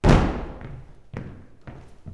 112 STOMP1-L.wav